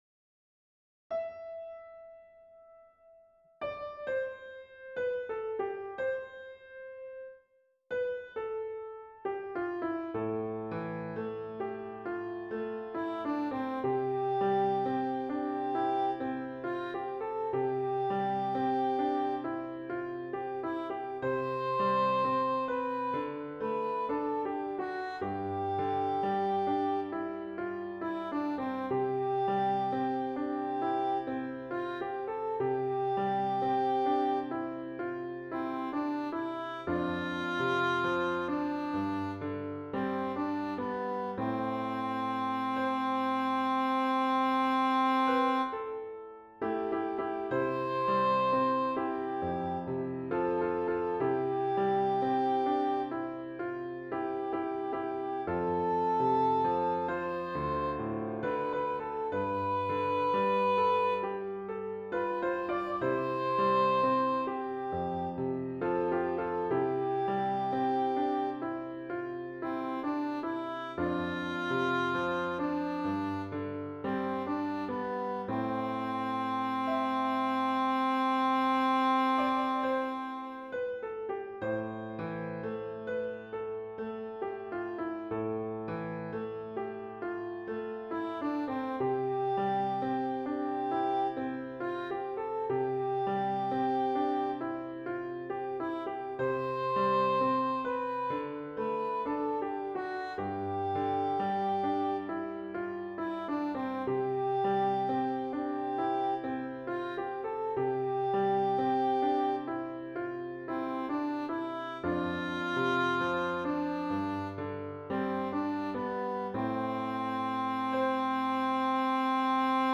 Voicing/Instrumentation: Choir Unison
Piano